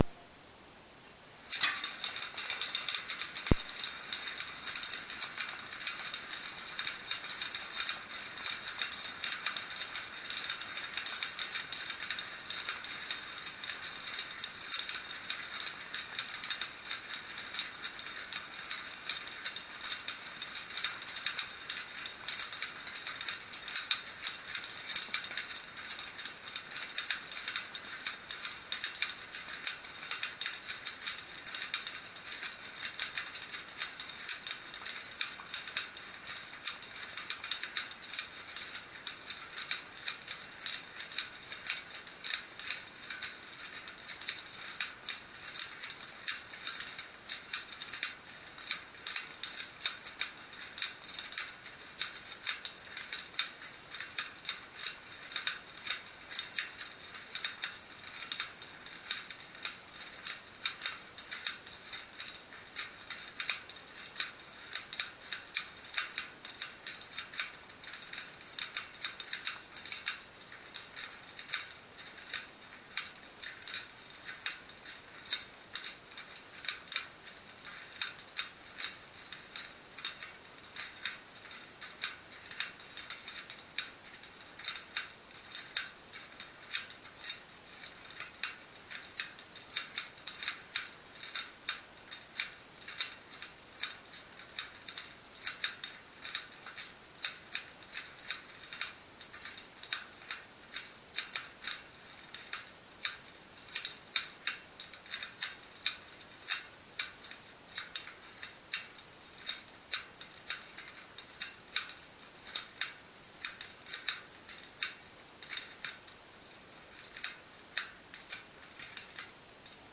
ungreased hub, shy two bearings on one side, spinning to a stop